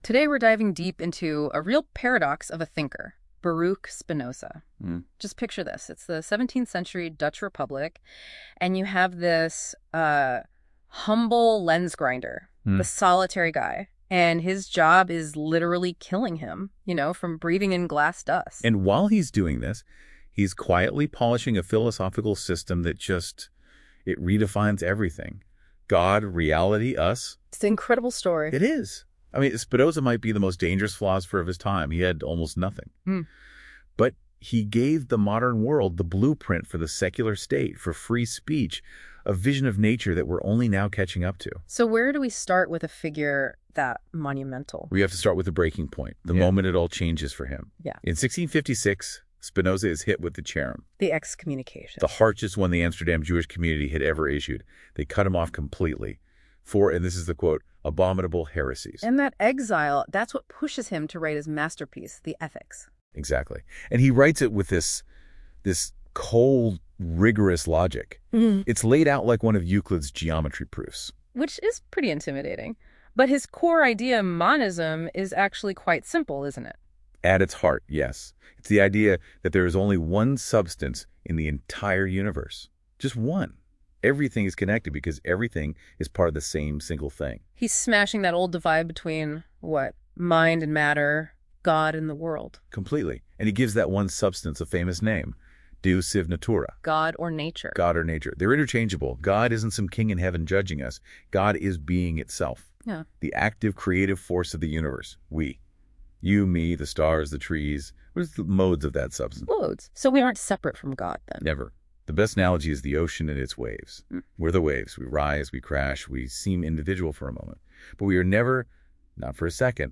Audio discussion about this essay.